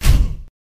neptunesGRINDING.wav